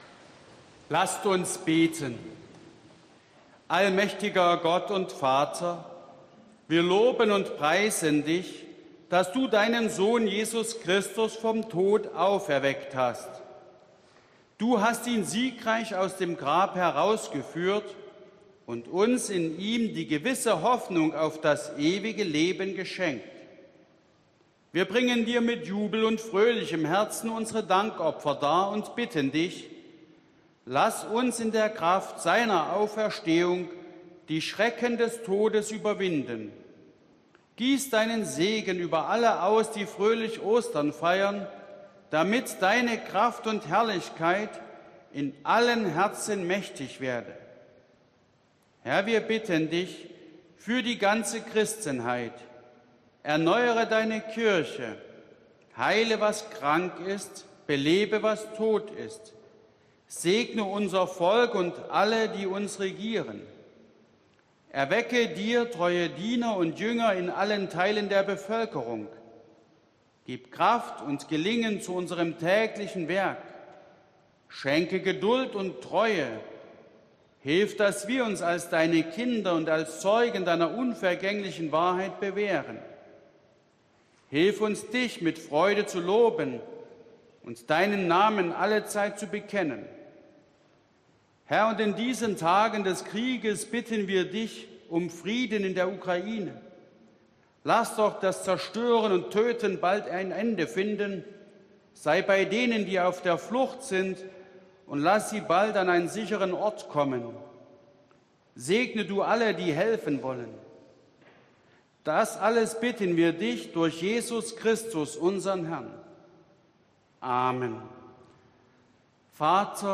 Audiomitschnitt unseres Gottesdienstes vom Sonntag Miserikordias Domini 2022.